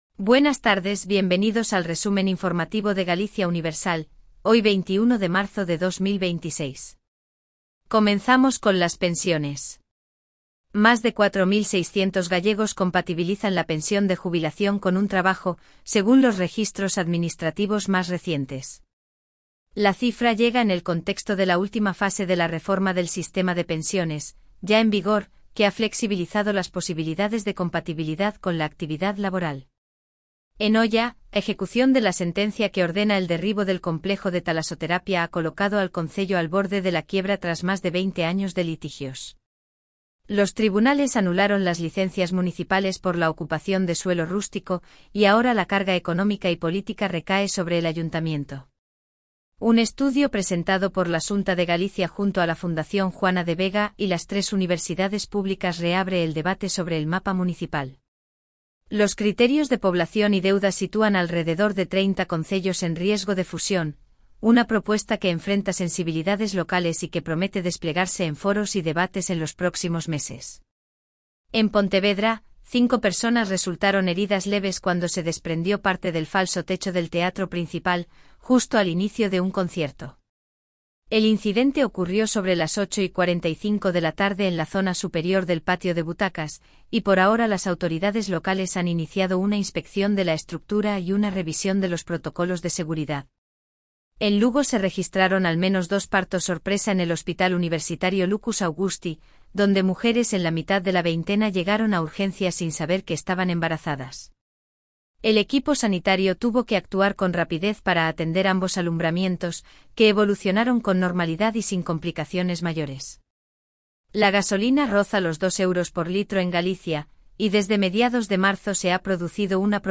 🎙 PODCAST DIARIO
Resumo informativo de Galicia Universal